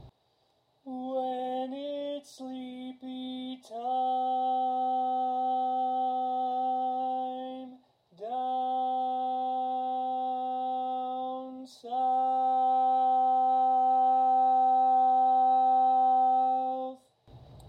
Key written in: C Major
Type: Barbershop
Each recording below is single part only.